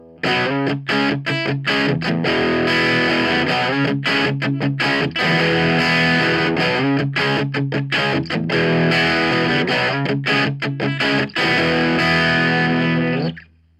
Crunch.mp3